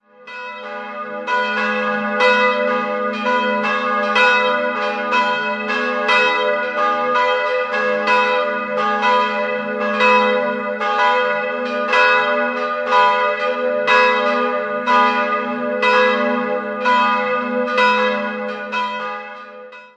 Das Langhaus entstand im Jahr 1737 neu und erhielt eine prächtige barocke Ausstattung. 3-stimmiges Geläut: gis'-h'-dis'' Die große Glocke wurde 1909, die kleine 1922 von Karl Hamm in Regensburg gegossen.